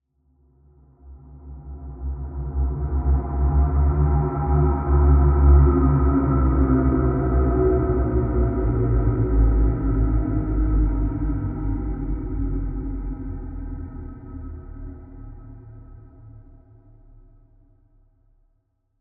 horror
Ghost Moan 19